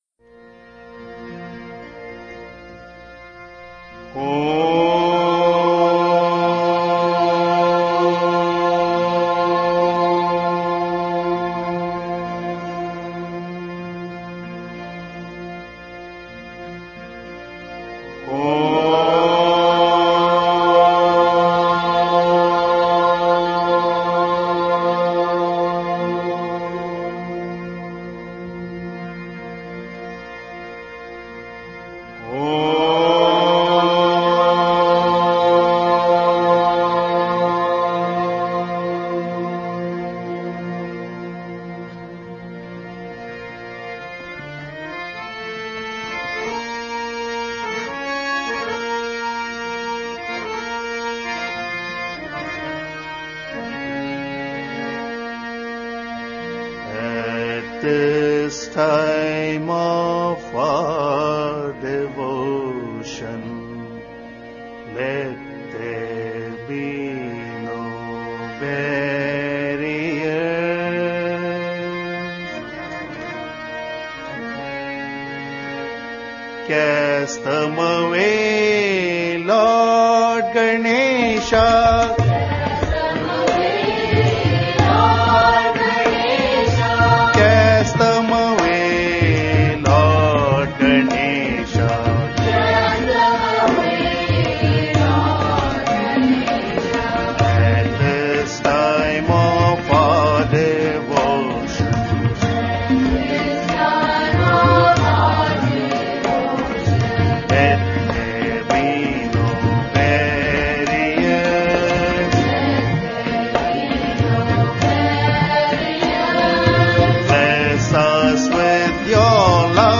1. Devotional Songs
Nattai
8 Beat / Keherwa / Adi
Medium Slow
1 Pancham / C
5 Pancham / G